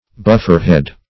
Search Result for " bufferhead" : The Collaborative International Dictionary of English v.0.48: Bufferhead \Buff"er*head`\, n. The head of a buffer, which recieves the concussion, in railroad carriages.